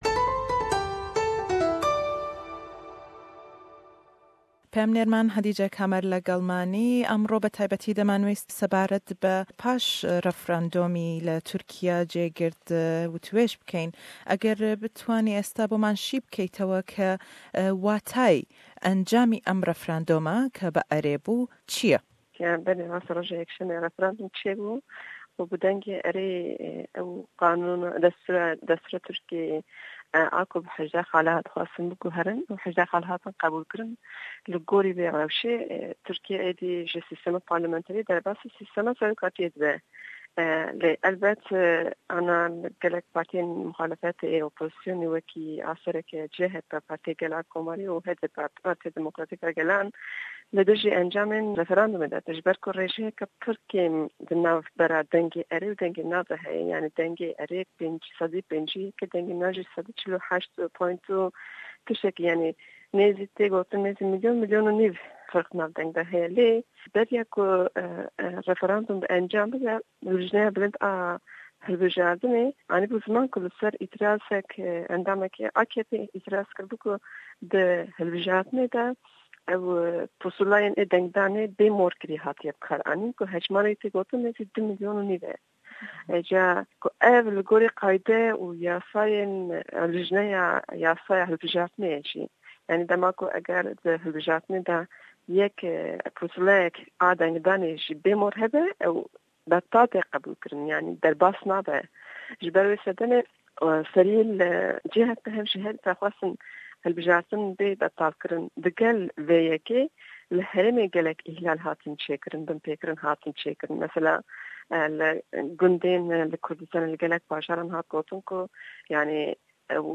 Lêdwanêk le gell peyamnêrman